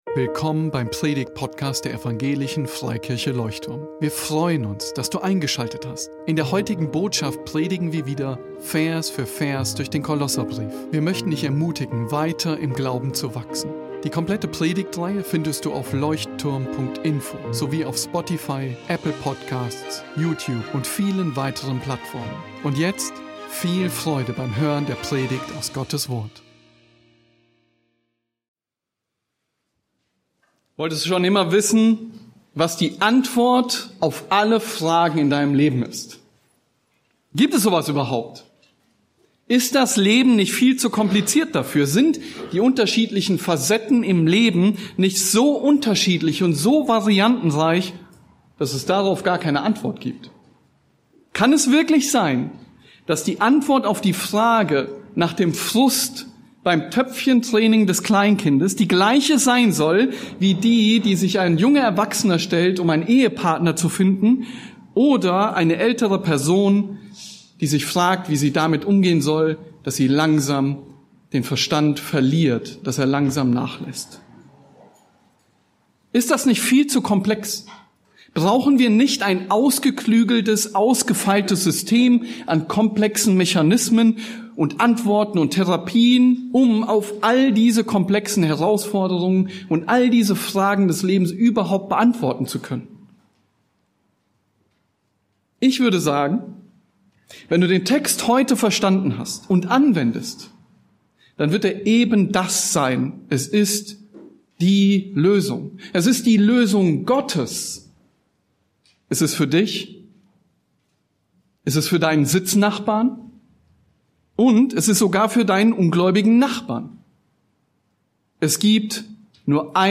Leuchtturm Predigtpodcast